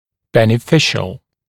[ˌbenɪ’fɪʃl][ˌбэни’фишл]благотворный, полезный